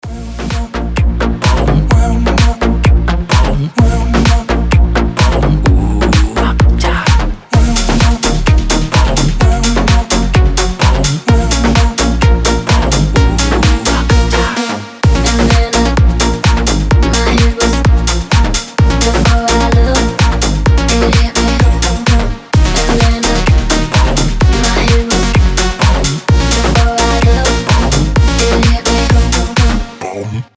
• Качество: 320, Stereo
Club House
Vocal House
Заводная клубная нарезка для бодрого звонка